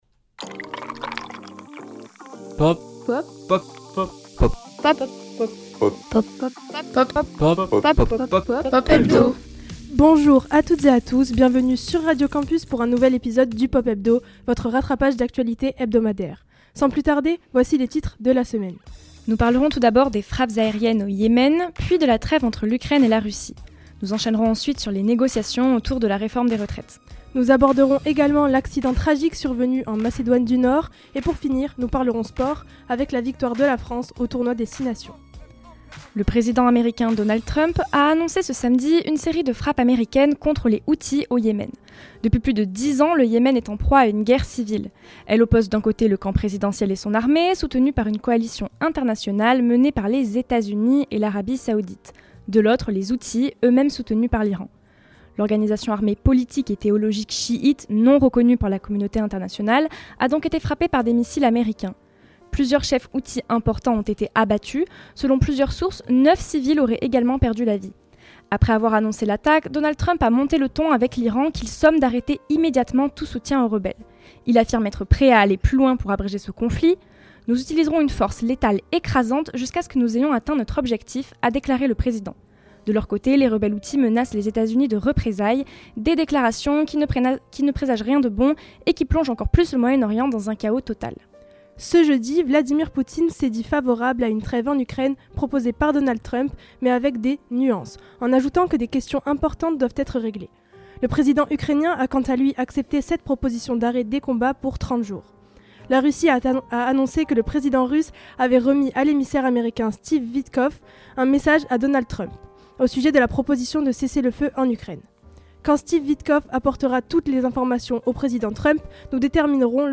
Programme à retrouver en direct tous les lundis entre 12h et 13h sur Radio Campus Bordeaux (merci à eux), et en rediffusion sur notre site Web et Spotify !